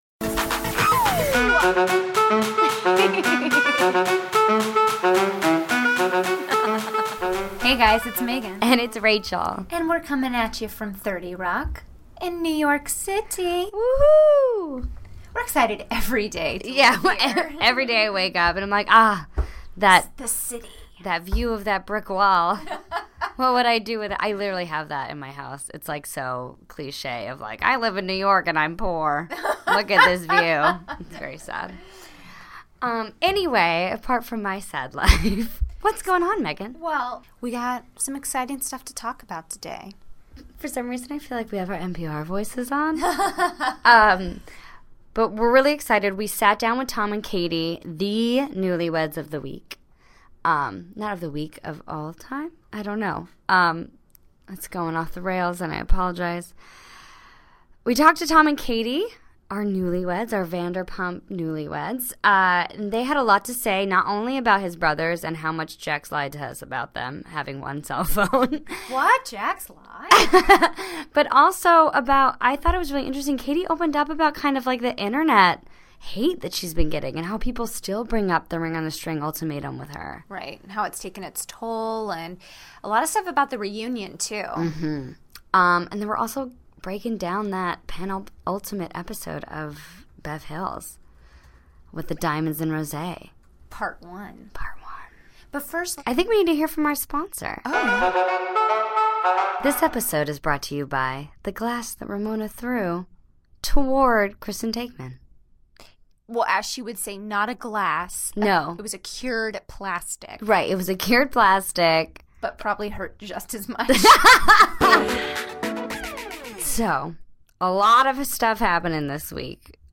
The Truth About the Triplets (Our Interview with Tom Schwartz and Katie Maloney)